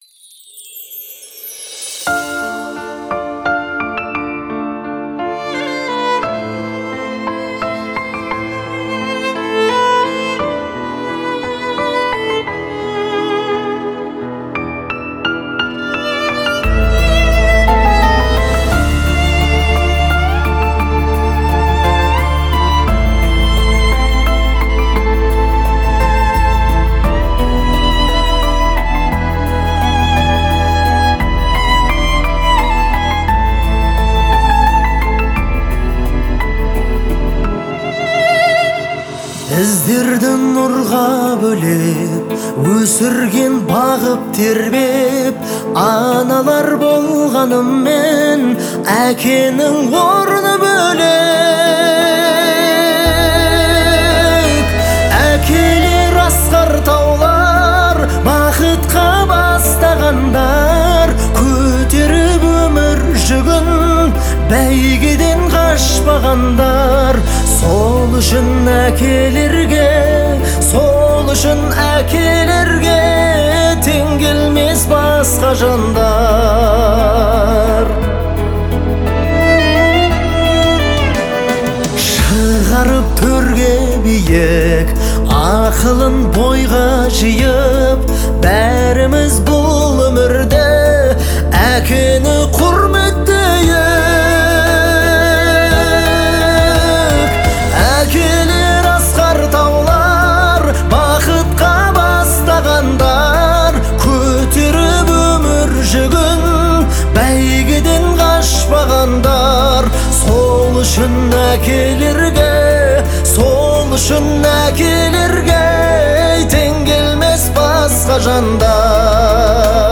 это трогательная песня в жанре казахской народной музыки
Звучание композиции отличается мелодичностью и душевностью